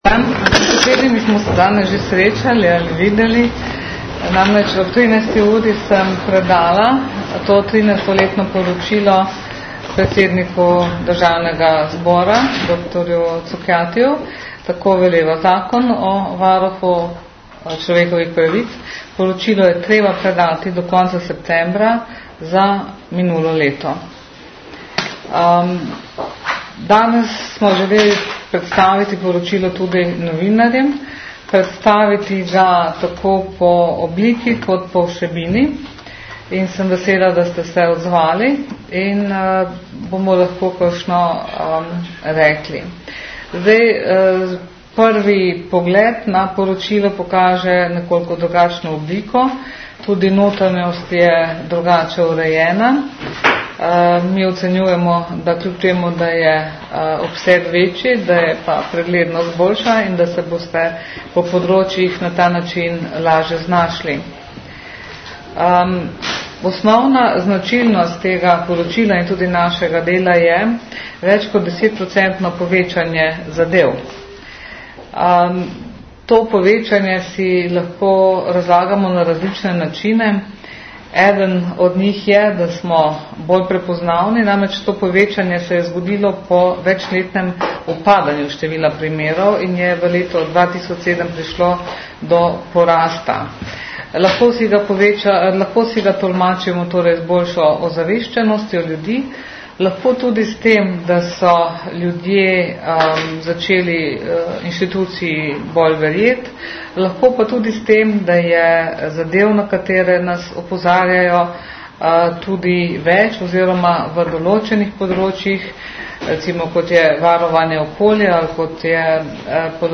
Po predaji poročila je varuhinja njegovo vsebino predstavila na novinarski konferenci v prostorih Varuha na Dunajski 56 v Ljubljani.
Zvočni posnetek novinarske konference (mp3, 5.3 MB)